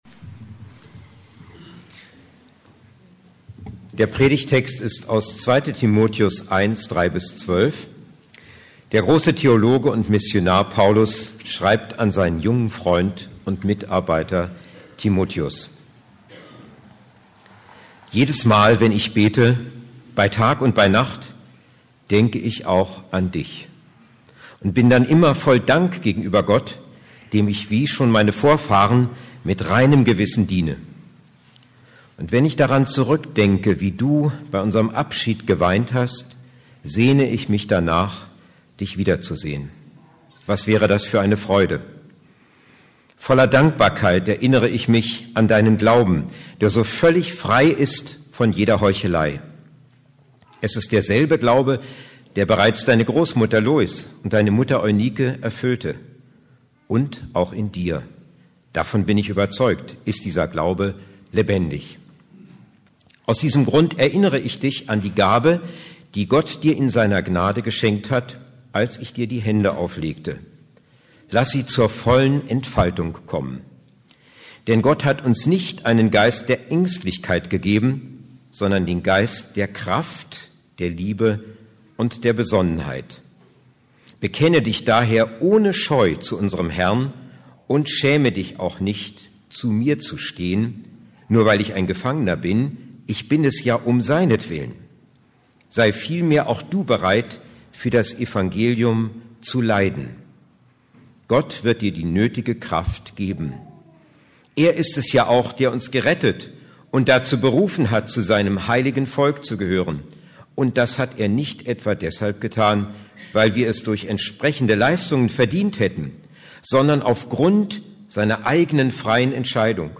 ~ Predigten der LUKAS GEMEINDE Podcast